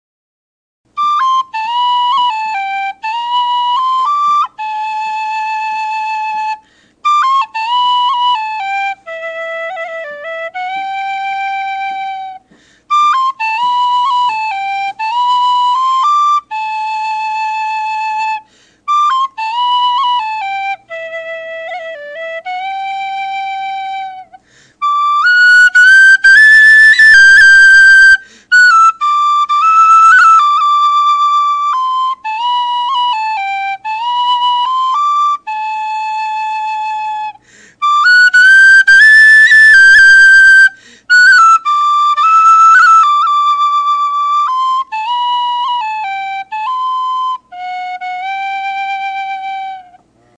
Sound clips of the whistle:
The South Wind (I’m sorry to say, but you can hear me breathing out of my nose right onto the microphone in this clip..egad!)
And it doesn’t have much start-of-note chiff. But it definitely has tone chiff throughout it’s sound.
Volume: Loud.